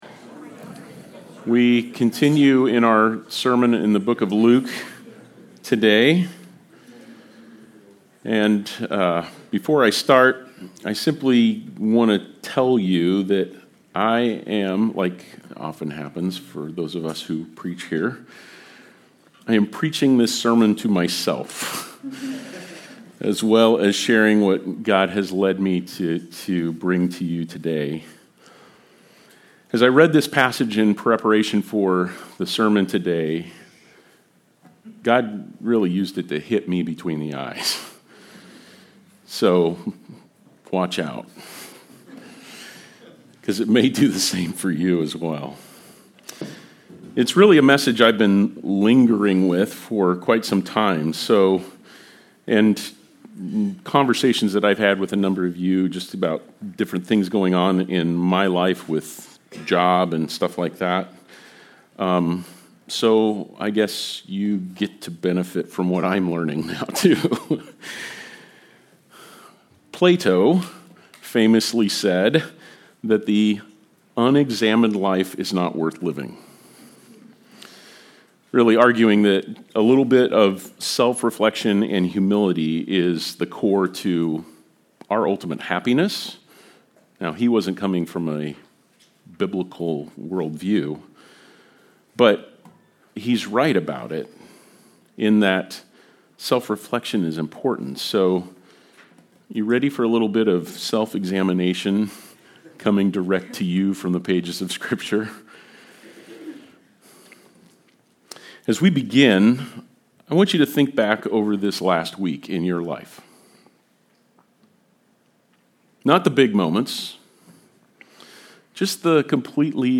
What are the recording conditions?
Series: LUKE: Good News for All People Passage: Luke 10:38-42 Service Type: Sunday Service